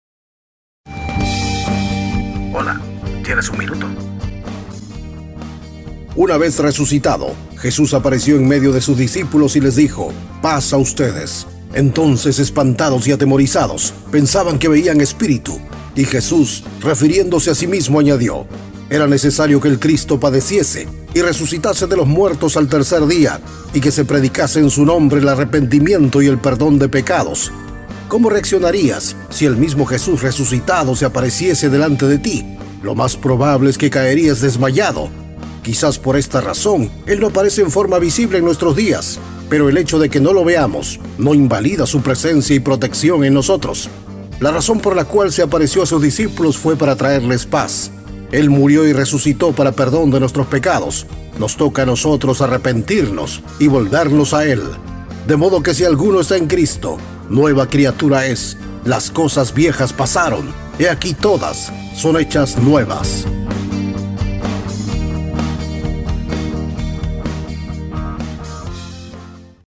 Programas radiales
Programa radial de 60 segundos, donde abordamos la vida de Jesús y sus palabras, aplicadas al mundo moderno.